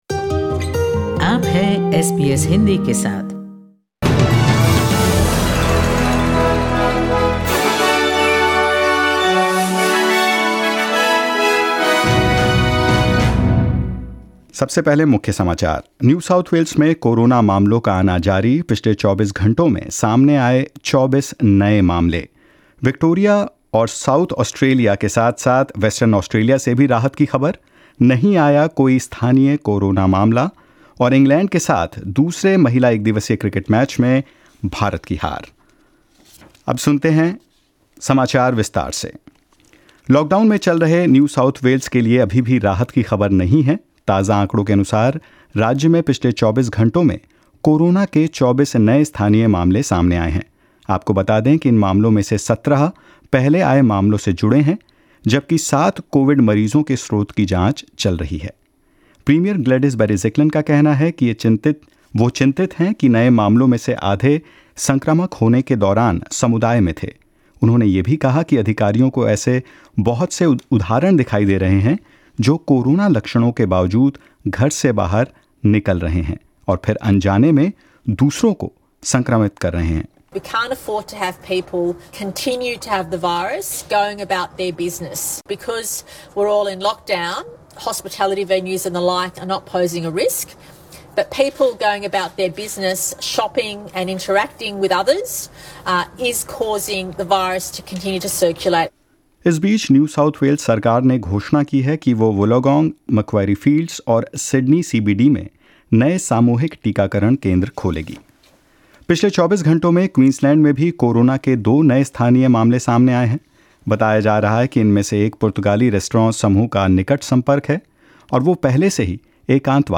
In this latest SBS Hindi News bulletin of Australia and India: Western Australia detects zero new local coronavirus cases on the third day of its snap lockdown; Dunkley 73, Cross five-for power England to a series win against Indian women cricket team and more.